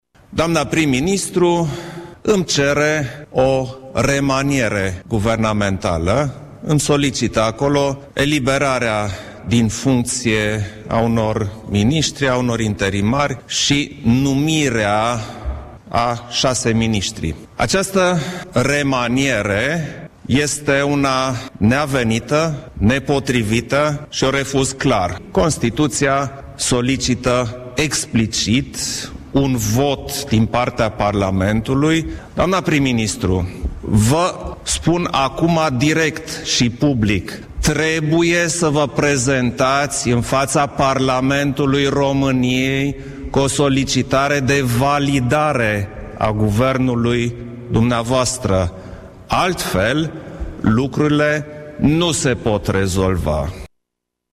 Preşedintele Klaus Iohannis i-a solicitat, în urmă cu puțin timp, premierului Viorica Dăncilă să se prezinte în faţa Parlamentului pentru validarea Guvernului:
12-sept-iohannis-remaniere.mp3